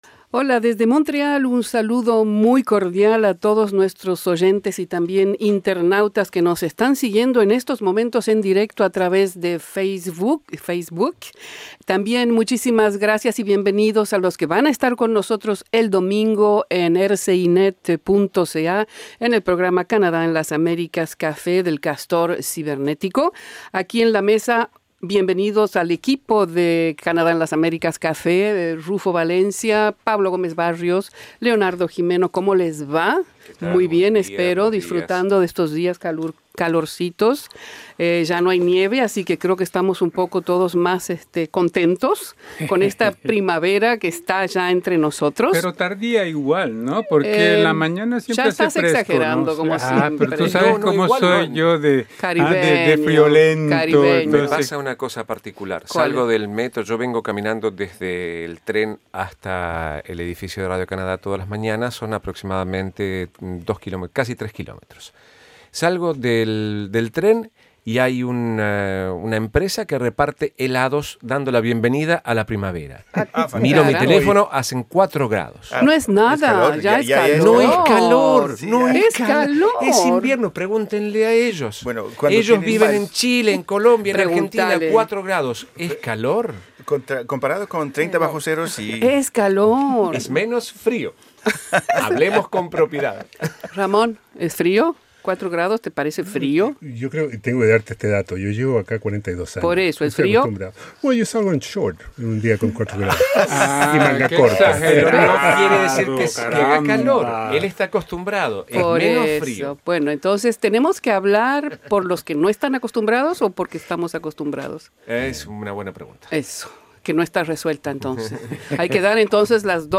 Invitados en estudio
Para los que nos quieran seguir todos los jueves en directo, tienen que ir la página Facebook RCI-Canadá en las Américas.